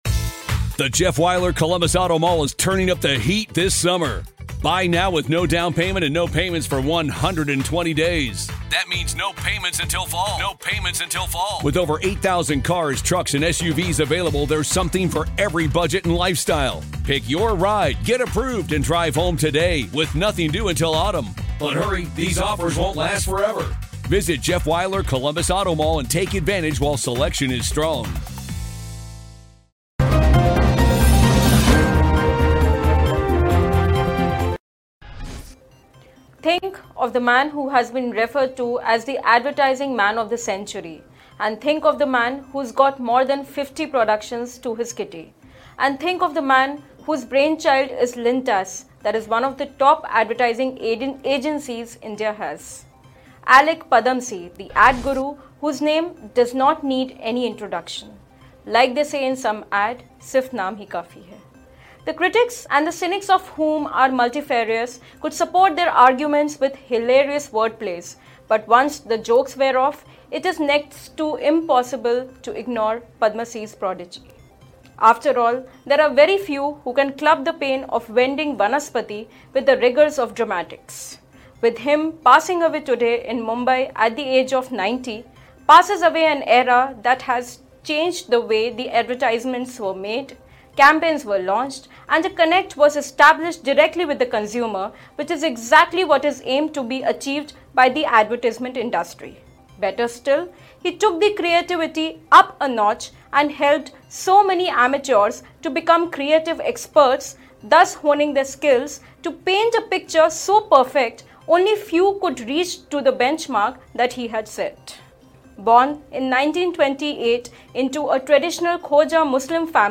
News Report / Alyque Padamsee: The Ad Guru and the Legend whose legacy will never be forgotten